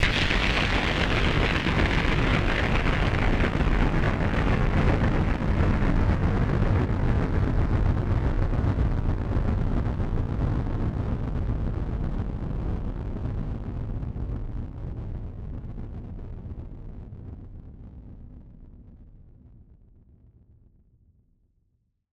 BF_DrumBombC-04.wav